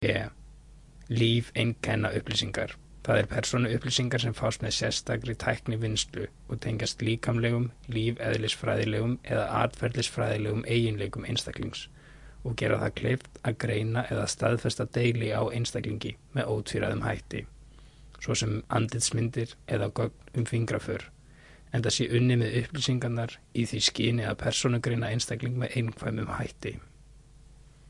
Tag: 语音 语言 声乐 口语 文字 声音